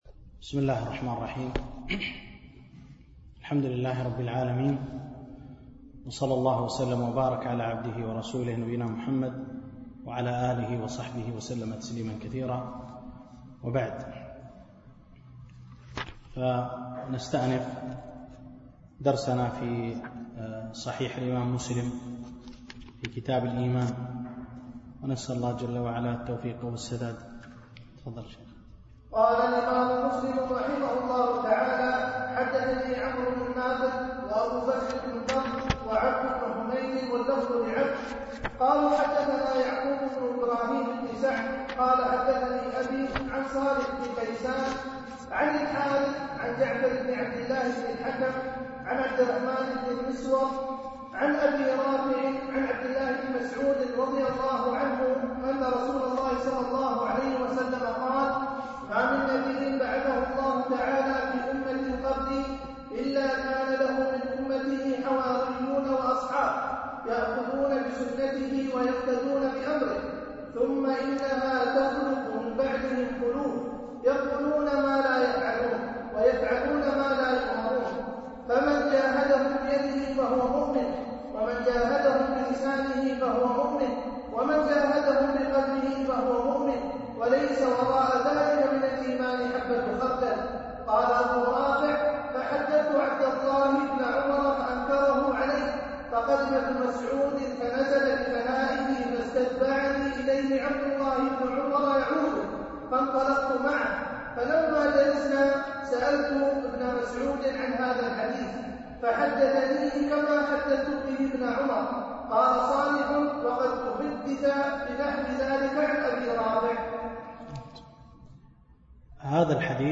الألبوم: دروس مسجد عائشة (برعاية مركز رياض الصالحين ـ بدبي)